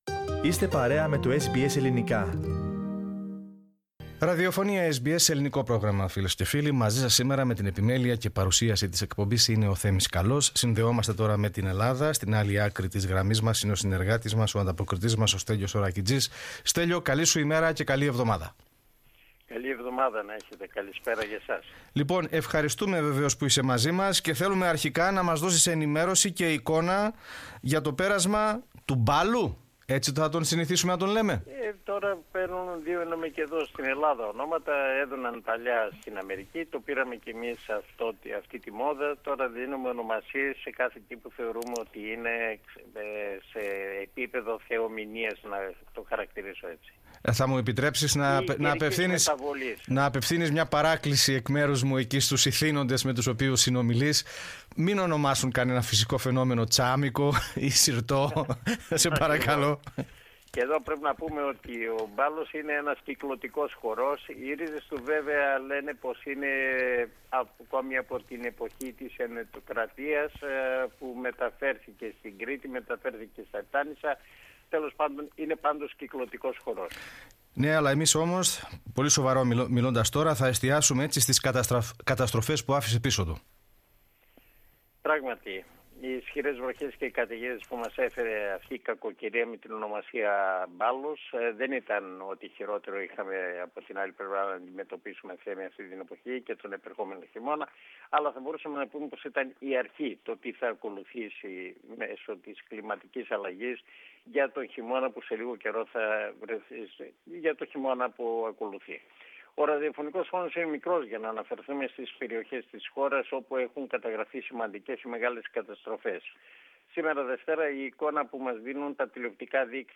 Πατήστε PLAY πάνω στην εικόνα για να ακούσετε την ανταπόκριση μας από την Ελλάδα.